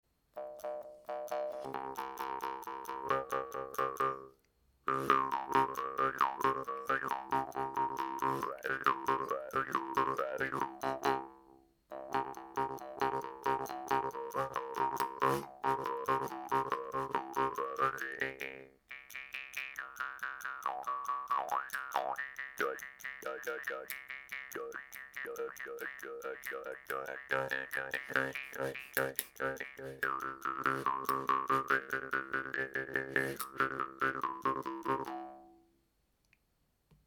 Cette guimbarde, imprimée 3D, est à conseiller pour les enfants et aux personnes n’aimant pas le contact avec le métal sur les lèvres car, contrairement aux guimbardes du Vietnam, elle ne présente ni partie piquante, ni arête coupante! Elle fera aussi le bonheur de toutes autres personnes à la recherche d’un son proche des guimbardes en bambou.